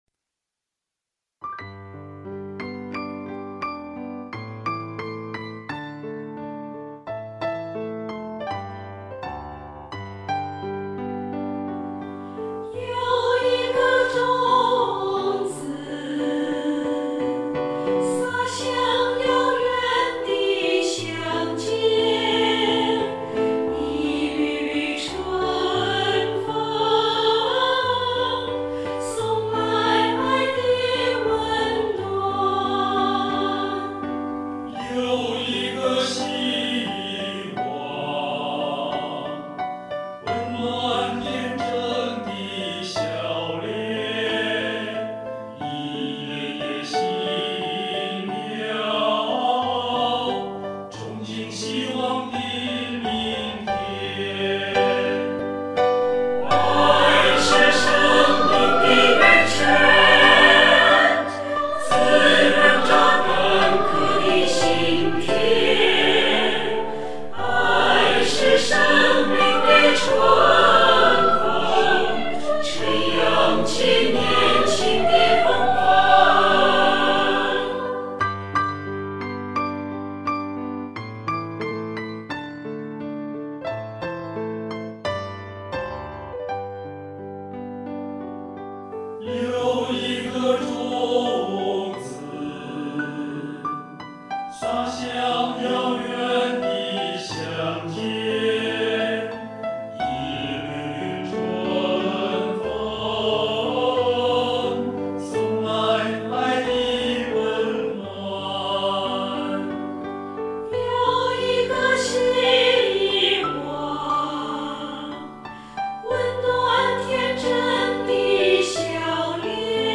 【正月十五闹花灯】合唱：希望的种子